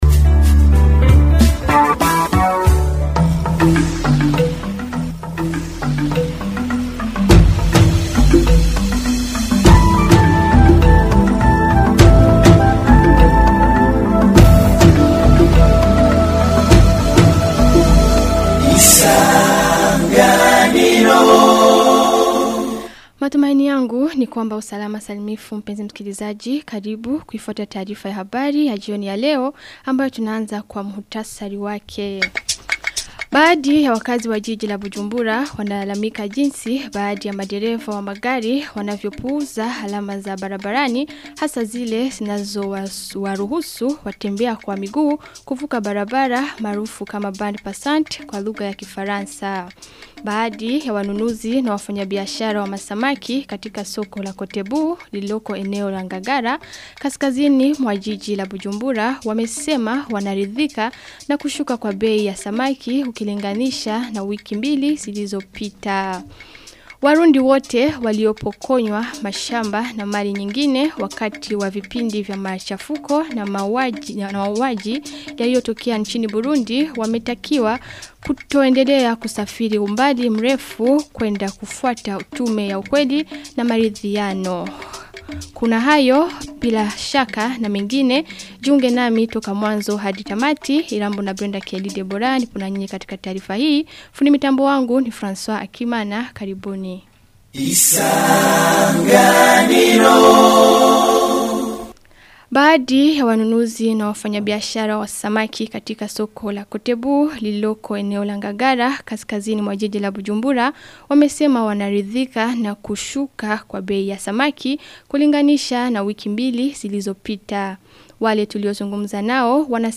Taarifa ya habari ya tarehe 19 Agosti 2025